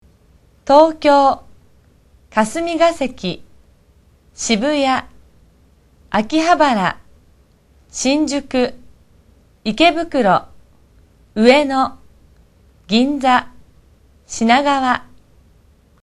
東京主要地名念法：